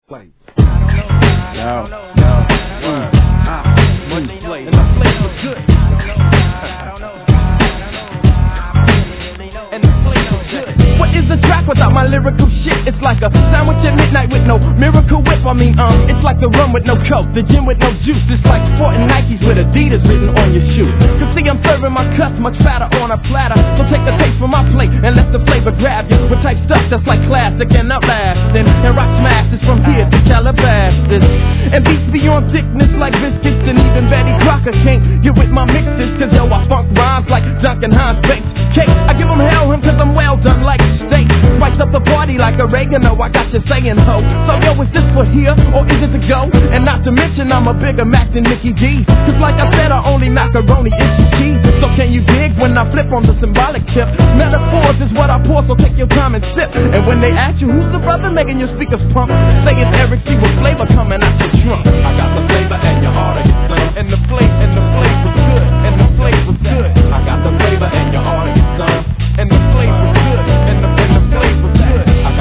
HIP HOP/R&B
1995年、そのJAZZサンプリングによるプロダクションは西海岸NEW SCHOOL!!